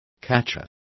Also find out how receptor is pronounced correctly.